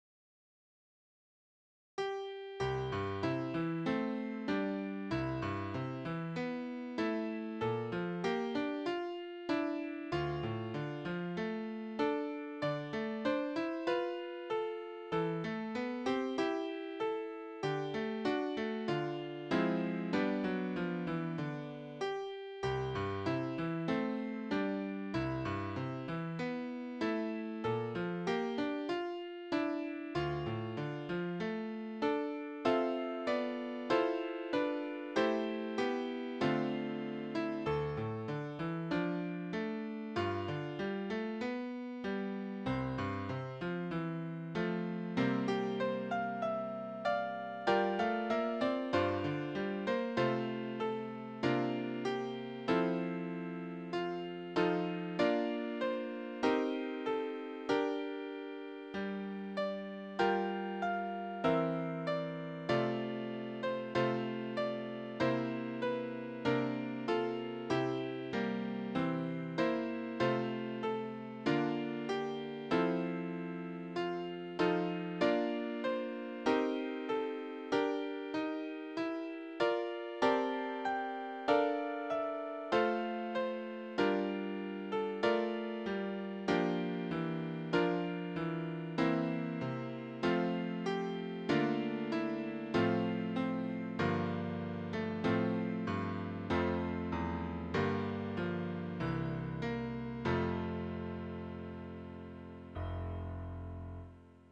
The Pilgrims Held a Feast, easy piano solo
Voicing/Instrumentation: Piano Solo
Instrumental/Instrumented